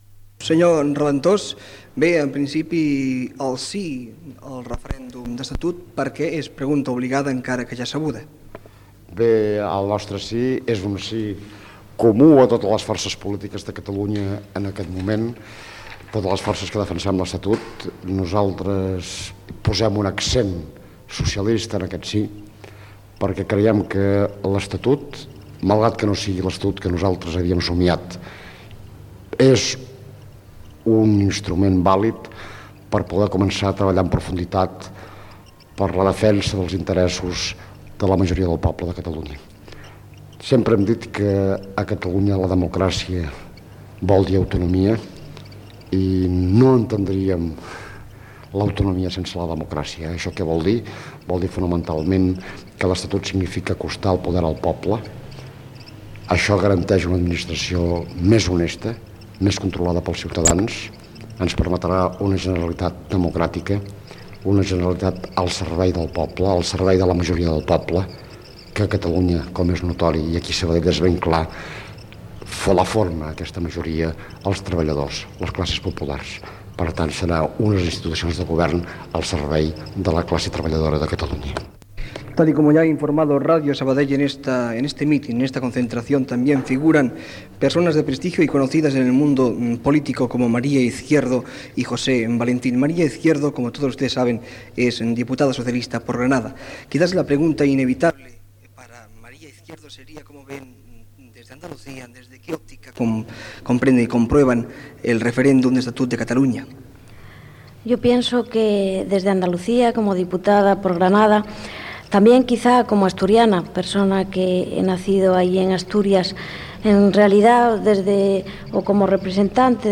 Entrevistes a Joan Raventós del Partit Socialista de Catalunya, María Izquierdo, diputada socialista per Granada, i Pablo Castellano, diputat socialista per Càceres, sobre el "Sí" al referèndum de l'Estatut d'Autonomia de Catalunya
Informatiu